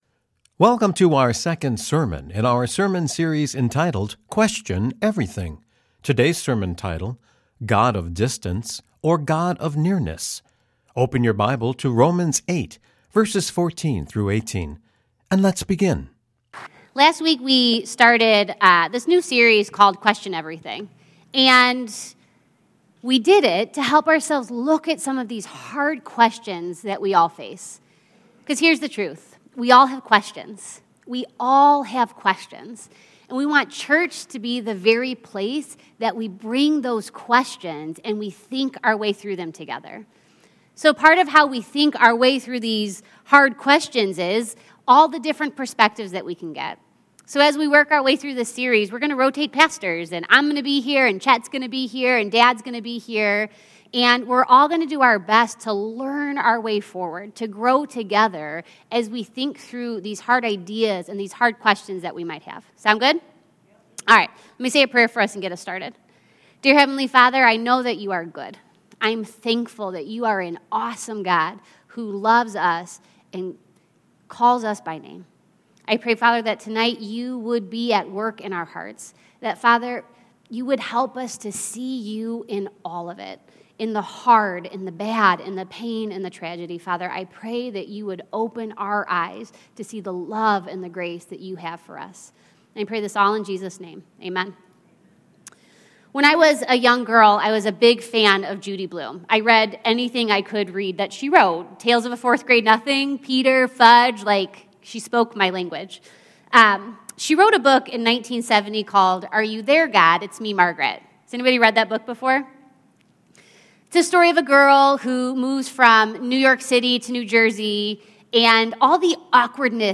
Romans 8:14-18 Columbia Station Campus